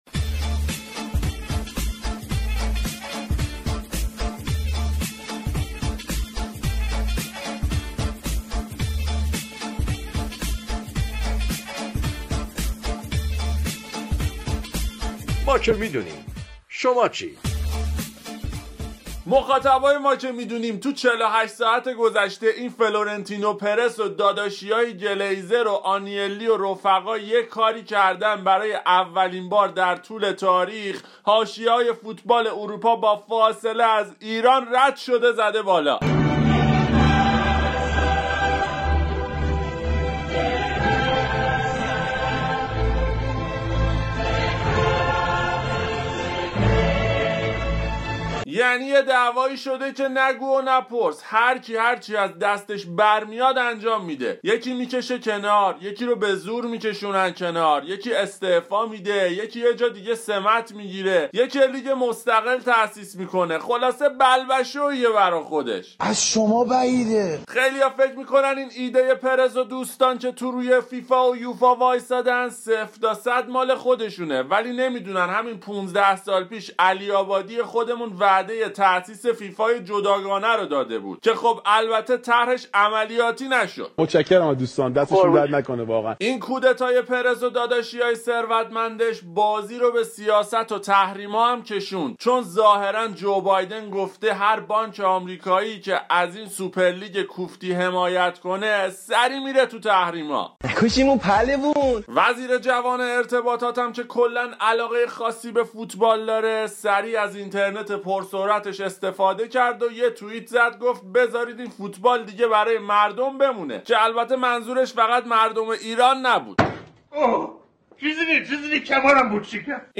شوخی رادیو با سوپر لیگ اروپا
سوژه اصلی آیتم طنز ما که می دونیم برنامه از فوتبال چه خبر رادیو ورزش، سوپر لیگ اروپا بود